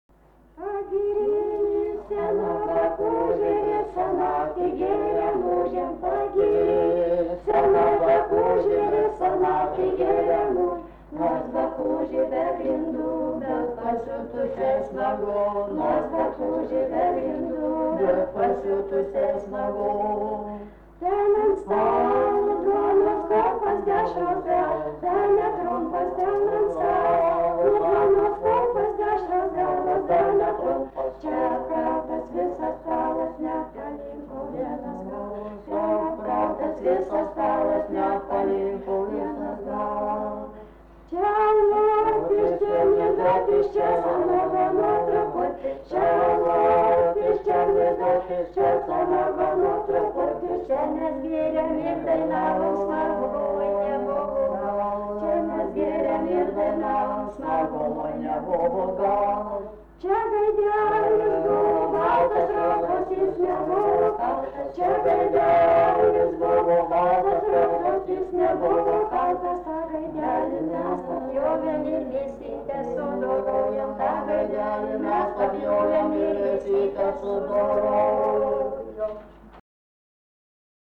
Menčikiai
vokalinis